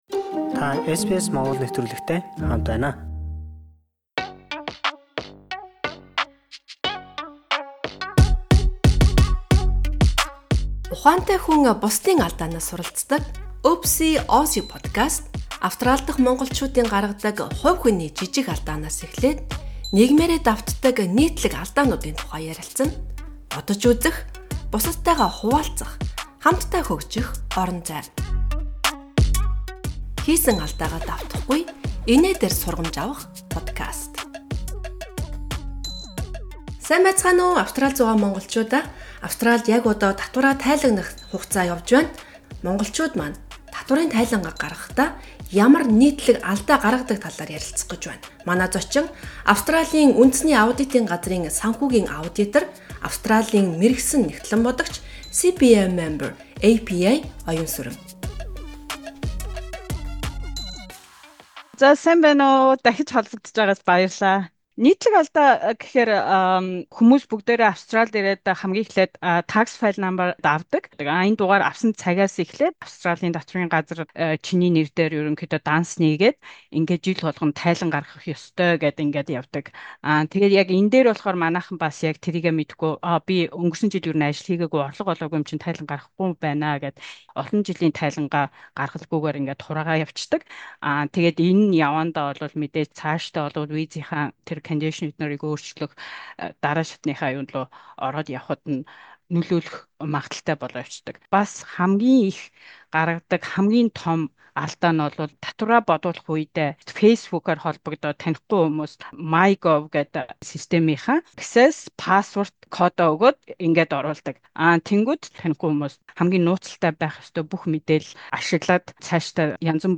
Австрали дах Монголчуудын гаргадаг хувь хүний жижиг алдаанаас эхлээд нийгмээрээ давтдаг нийтлэг алдаануудын тухай ярилцана.